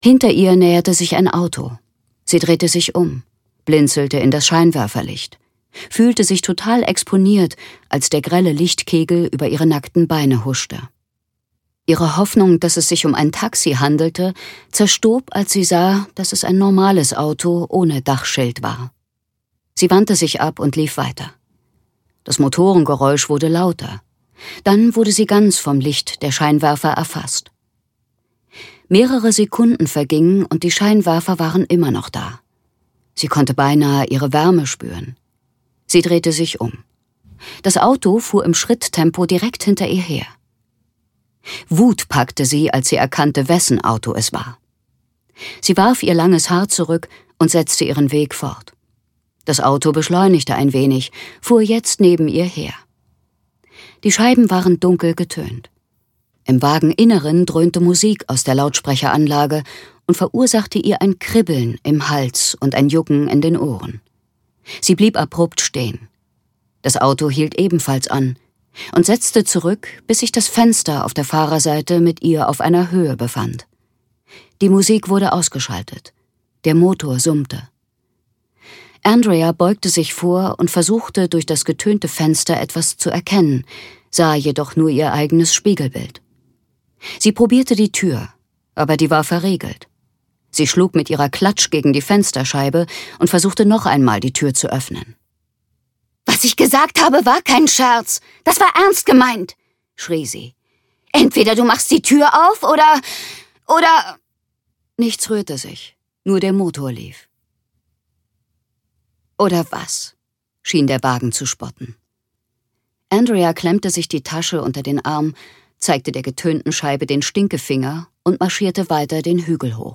Das Mädchen im Eis (DE) audiokniha
Ukázka z knihy